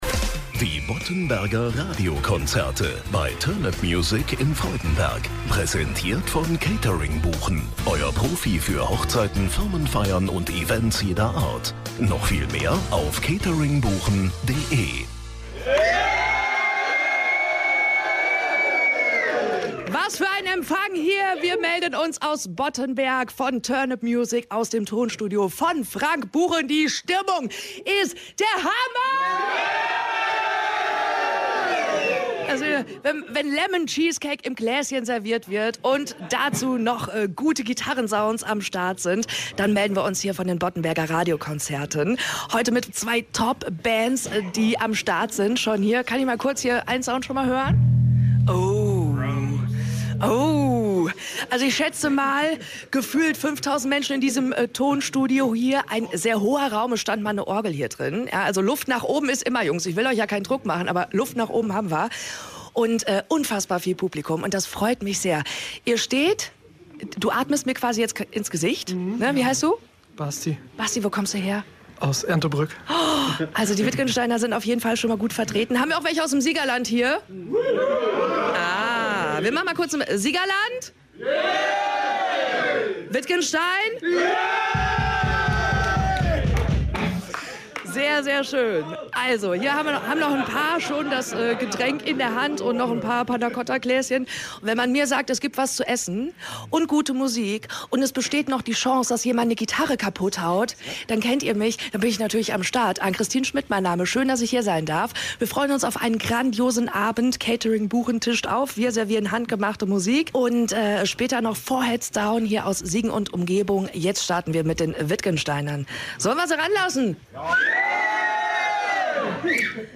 Punk Rock (!) aus Siegerland und Wittgenstein.
Wild, laut und lecker war's!